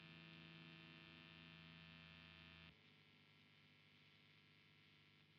弦に触れてないときのノイズが気になってました。
使用前、使用後、の順です。
少しは減ったんじゃないでしょうか。スペアナで見ると12dBちかく落ちてるように見えますが聞いたかんじはそれほどでもないかな。
ただ、これはリアの音で、フロントだとまだちょっと気になるレベルですね。